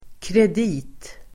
Uttal: [kred'i:t]